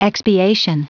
Prononciation du mot expiation en anglais (fichier audio)
Prononciation du mot : expiation